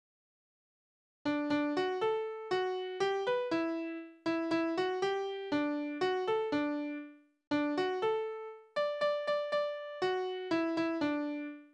Balladen: Des Markgrafen Töchterlein
Tonart: D-Dur
Taktart: 3/4
Tonumfang: Oktave
Besetzung: vokal